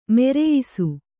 ♪ mereyisu